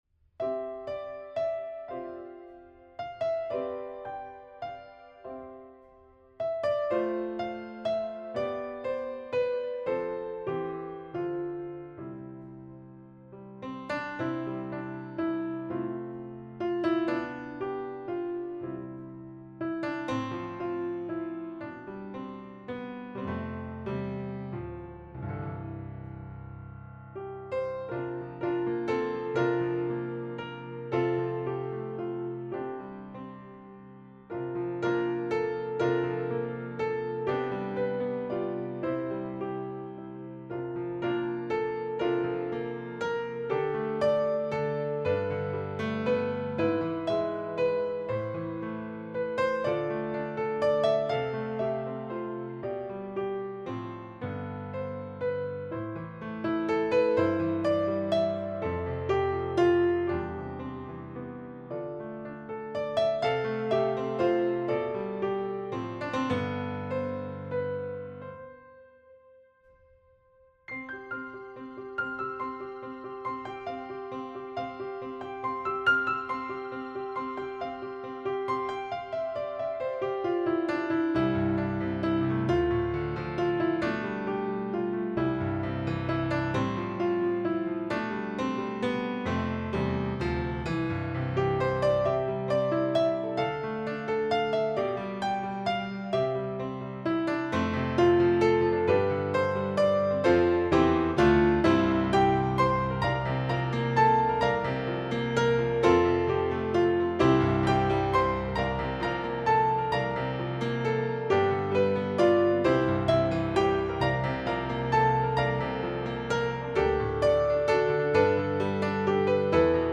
Voicing: Piano Collection